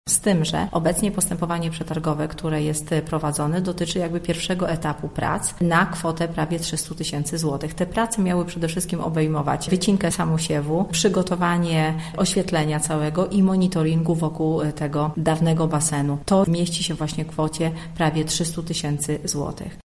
– Projekt obejmuje prace, których koszt sięga 2 milionów złotych, ale zadanie będzie podzielone na etapy – mówi Danuta Madej, burmistrz Żar.